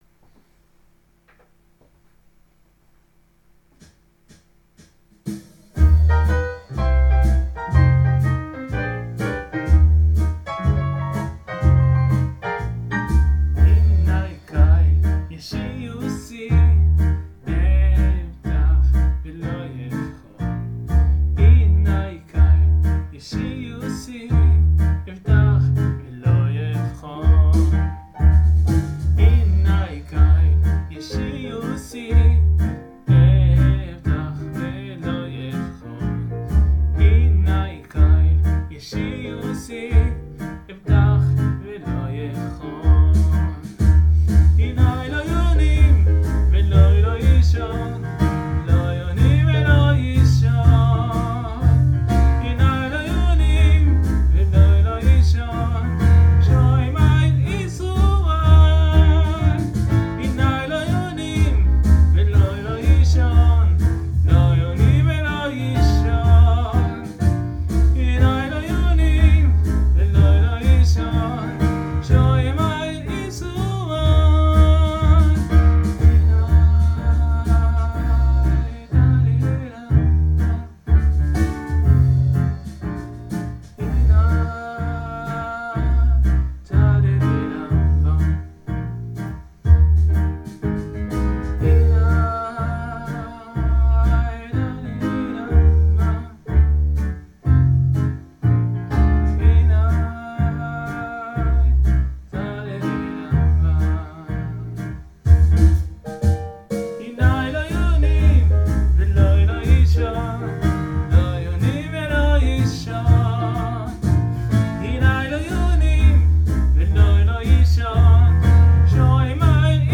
לחן קצת נועז מהיום בבוקר.. למי שאוהב ג'אזזזז